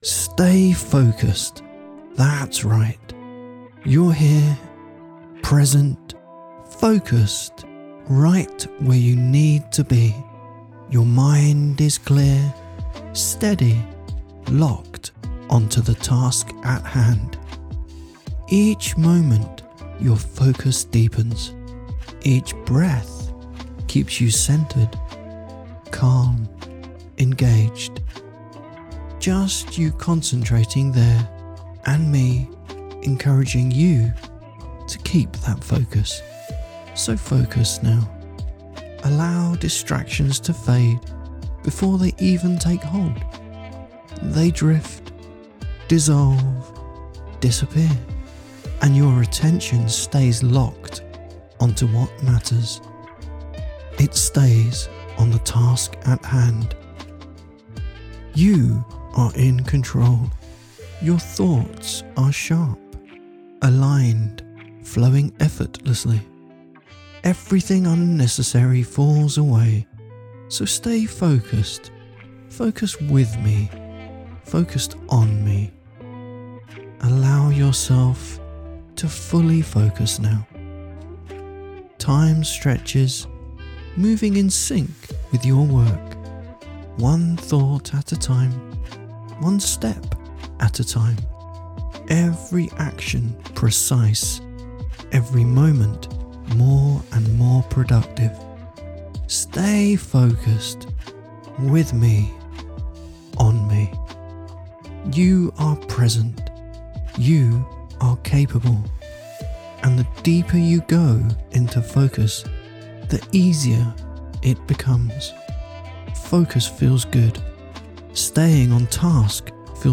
Stay Focused - lo-fi productivity loop
— A loop to help you focus at work or whenever you need to do a task. Some lo-fi beats in the background helping you just switch into the focus flow-state.